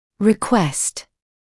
[rɪ’kwest][ри’куэст]просить; запрашивать; просьба